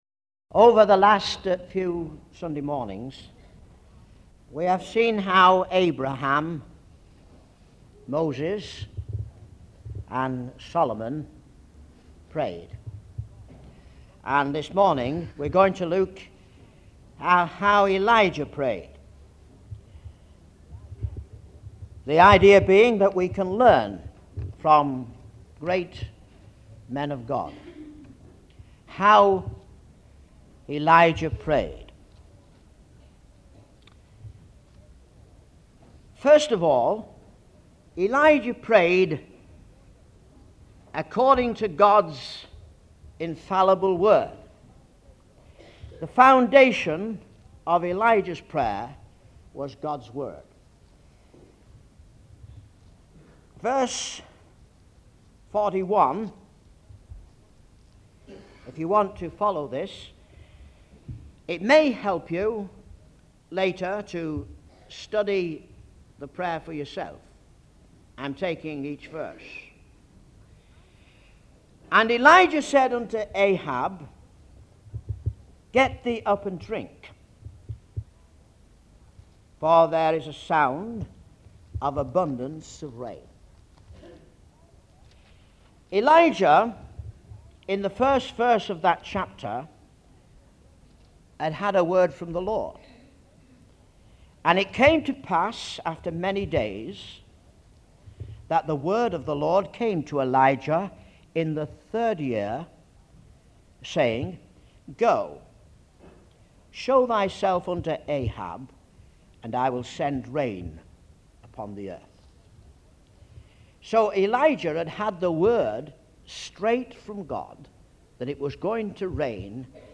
In this sermon, the speaker focuses on the story of Elijah and the prophets of Baal.